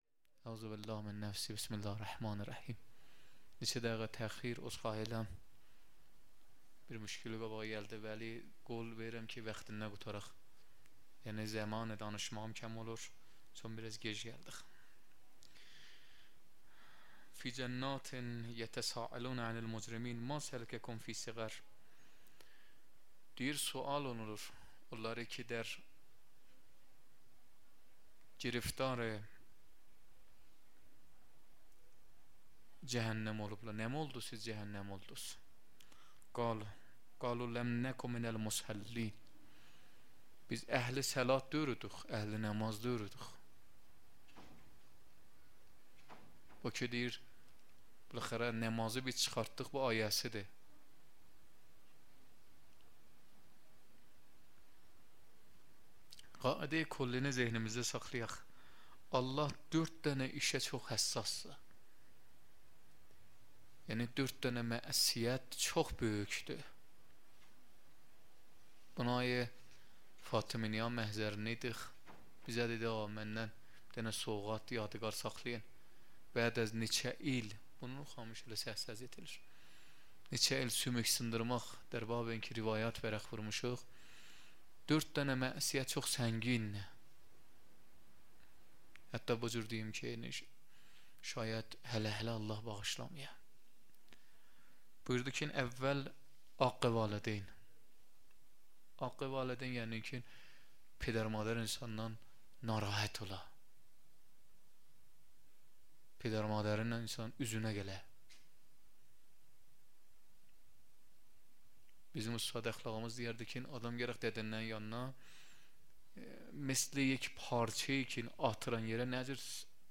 0 0 سخنرانی
مراسم هفتگی